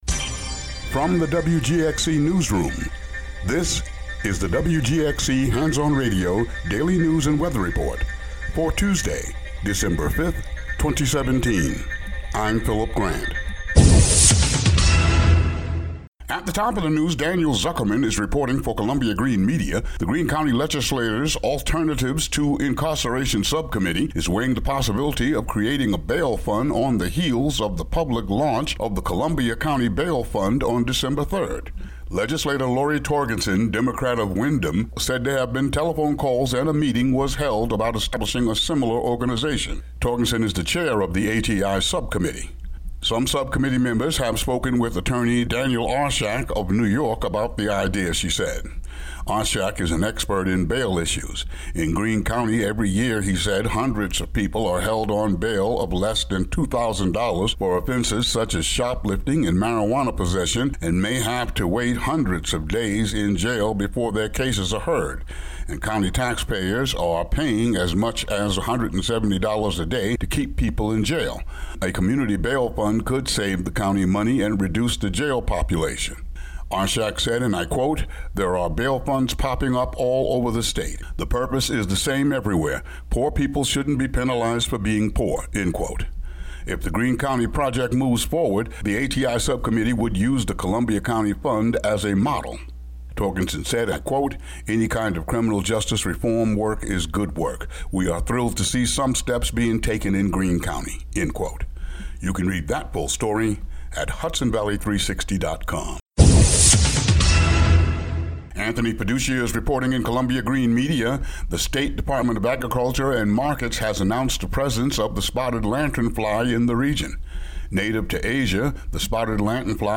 Daily local news for Tue., Dec. 5.